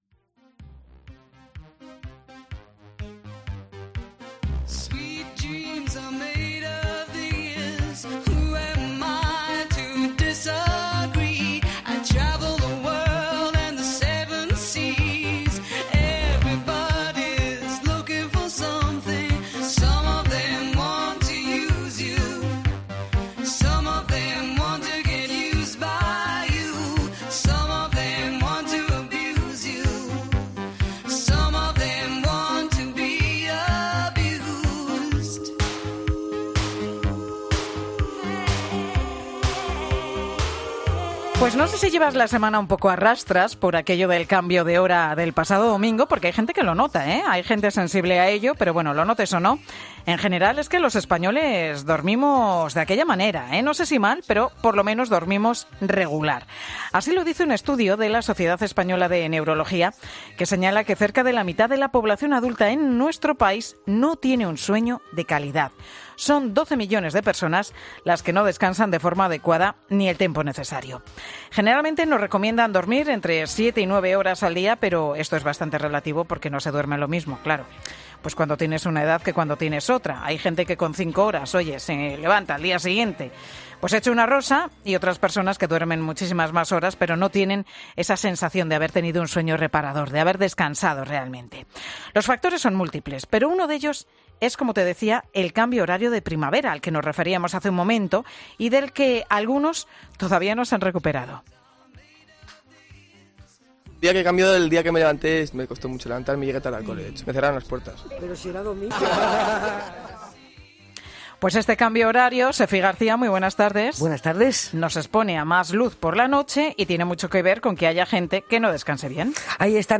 En 'Mediodía COPE' hablamos con un especialista del sueño que nos explica las razones por las que nos cuesta conciliar el sueño y cómo podemos evitarlo